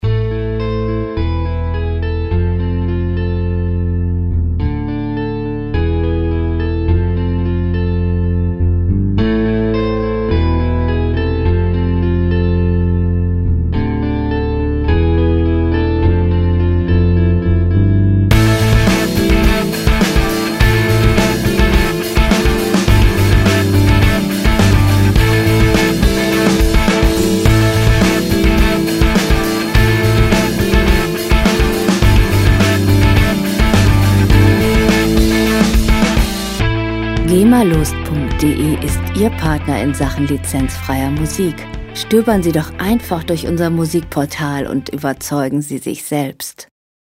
Rockmusik - Harte Männer
Musikstil: Emo
Tempo: 110 bpm
Tonart: A-Moll
Charakter: rebellisch, ungezähmt
Instrumentierung: E-Gitarre, E-Bass, Drums